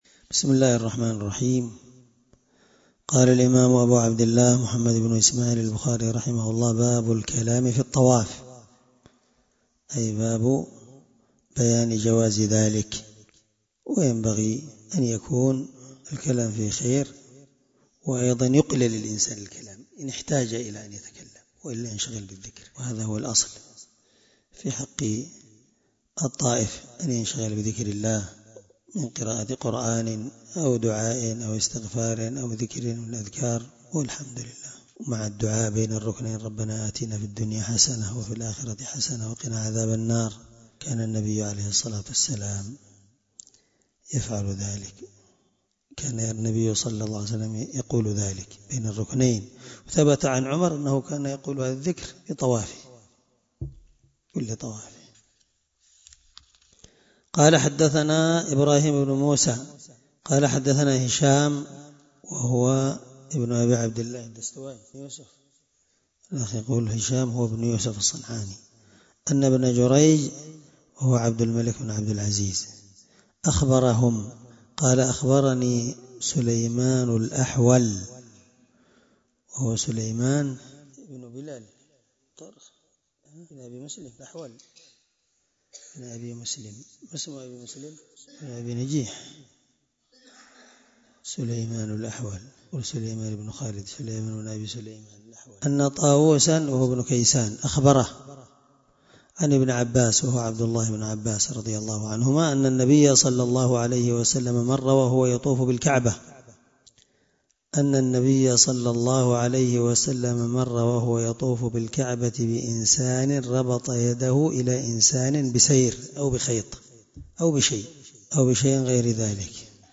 الدرس46 من شرح كتاب الحج حديث رقم(1620-1621 )من صحيح البخاري